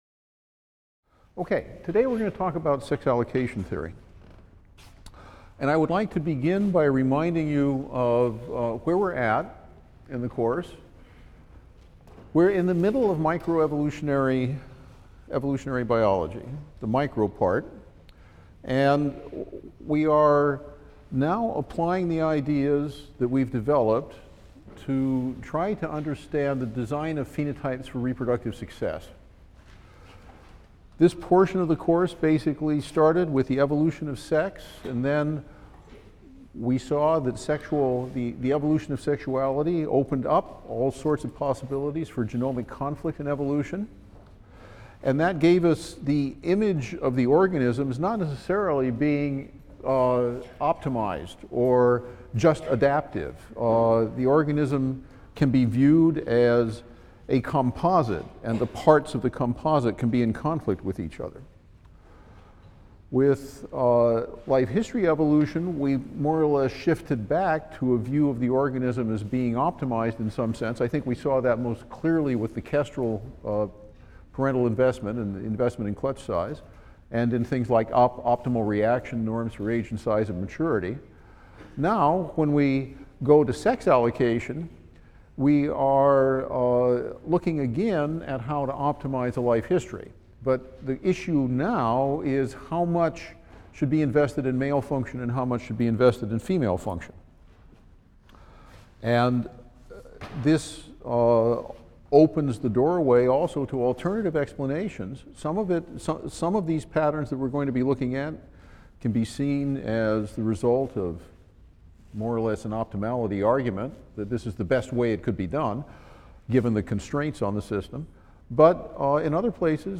E&EB 122 - Lecture 12 - Sex Allocation | Open Yale Courses